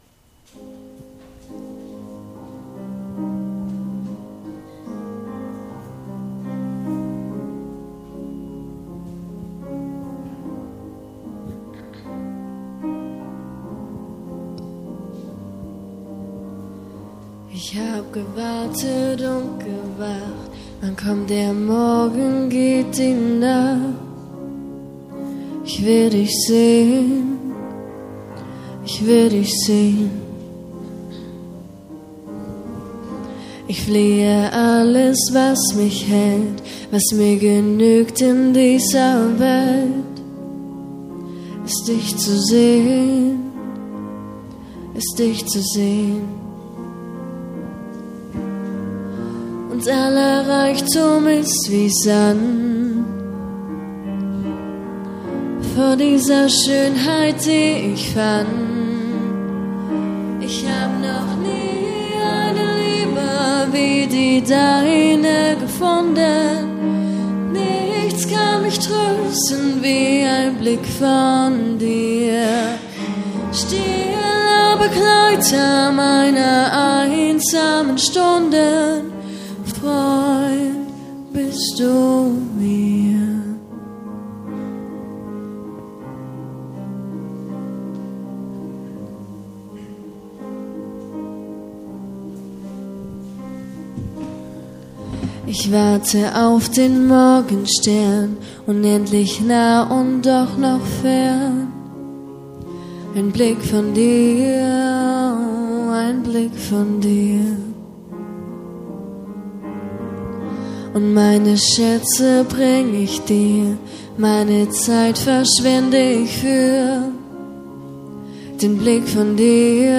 05.06.2022 – Gottesdienst
Predigt und Aufzeichnungen